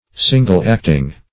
Single-acting \Sin"gle-act`ing\, a.